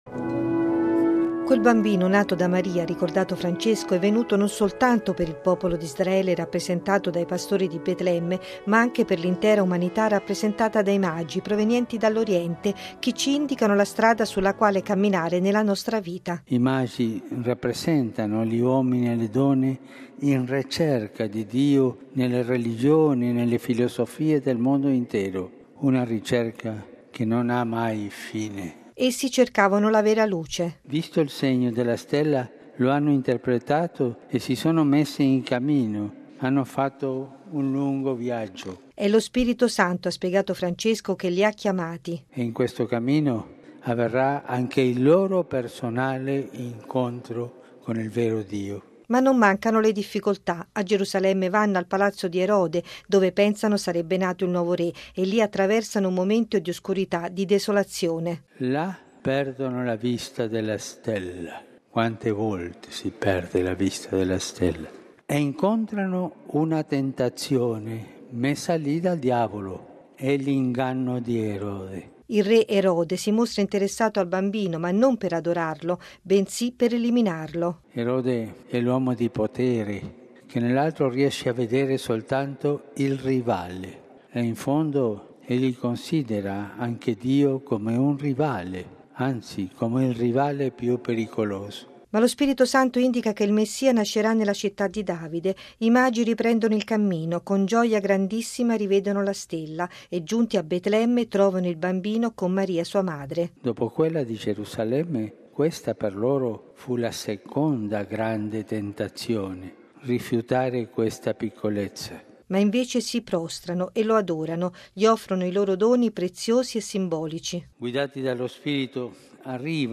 La ricerca di Dio non ha mai fine e i Magi ci indicano la strada per entrare nel mistero: così Papa Francesco nell’omelia della Messa, celebrata per la Solennità dell’Epifania nella Basilica di San Pietro, accompagnata dal Coro della Pontificia Cappella Sistina.